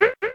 DK almost falling.
Source SNES Emulator
DK_(almost_falling).oga.mp3